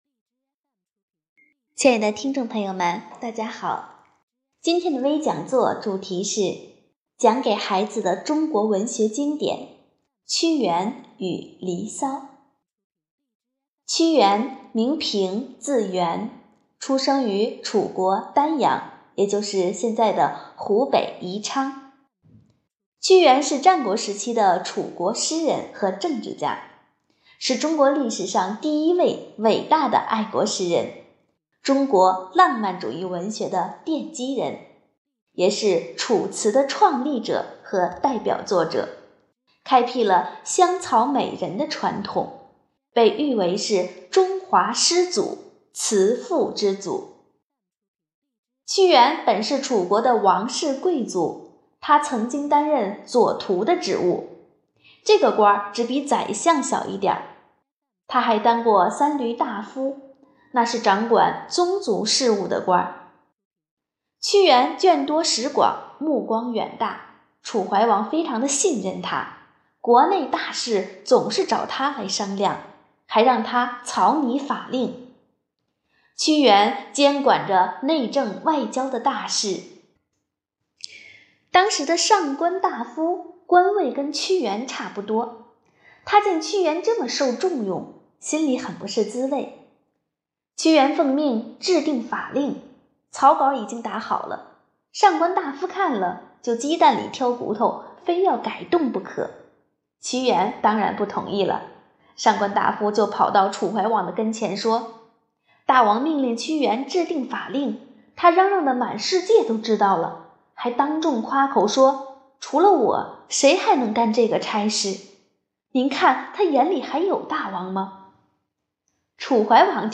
活动预约 - 【讲座】解读中国文学经典——屈原与《离骚》